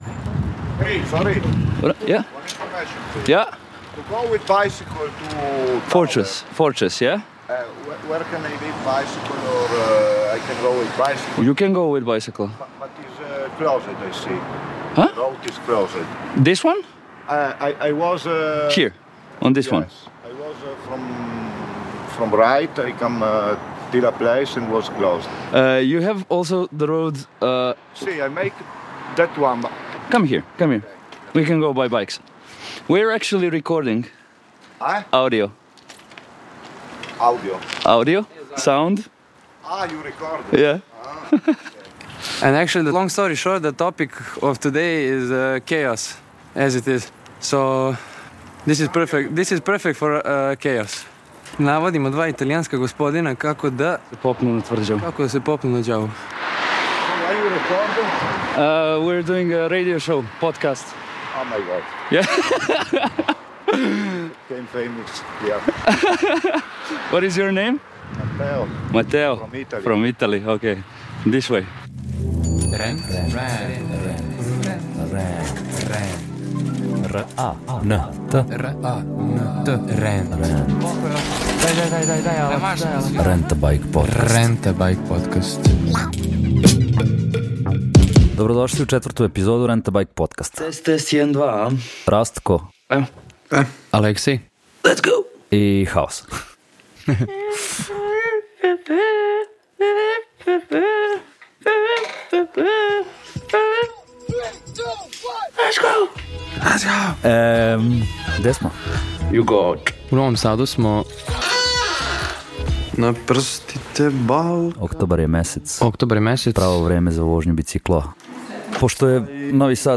Vozeći bajs po Novom Sadu, jednog oktobarskog dana, prisećali smo se naših haosa sa Malomfestivala. Bilo je raznih haotični, interesantnih ali i neuspelih intervjua i momenata.